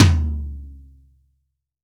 Index of /90_sSampleCDs/AKAI S6000 CD-ROM - Volume 3/Drum_Kit/ROCK_KIT1
MIX2 LTOM -S.WAV